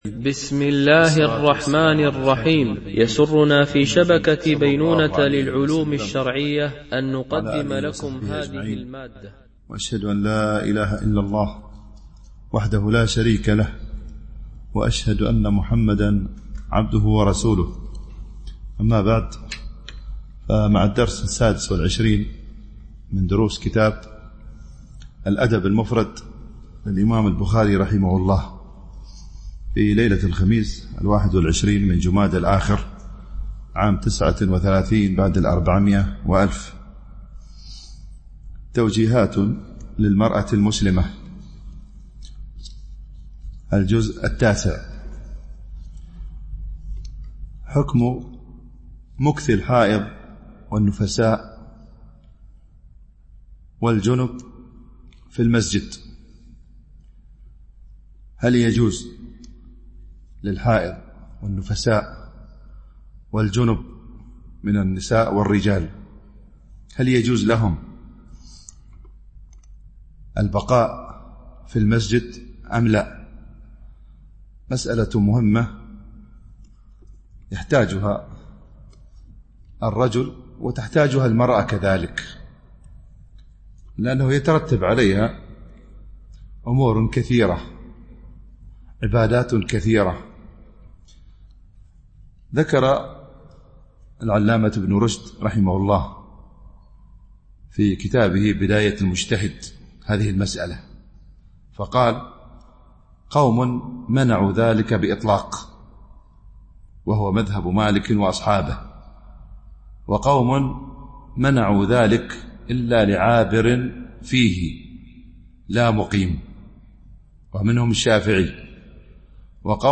شرح الأدب المفرد للبخاري ـ الدرس 26 ( الحديث 132-137 )